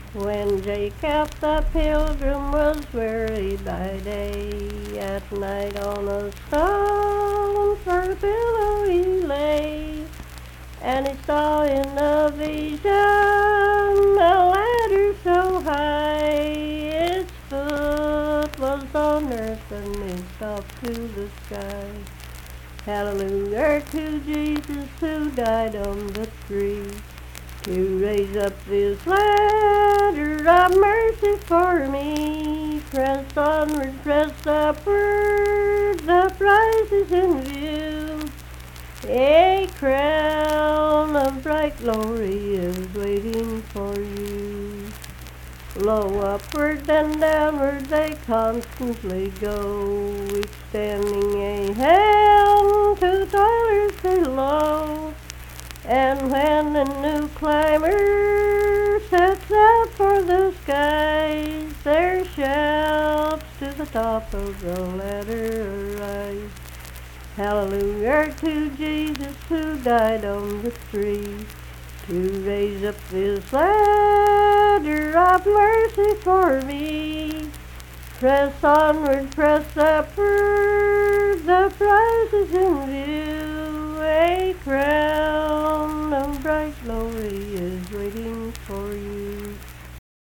Unaccompanied vocal music performance
Verse-refrain 4(4).
Hymns and Spiritual Music
Voice (sung)